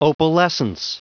Prononciation du mot opalescence en anglais (fichier audio)
Prononciation du mot : opalescence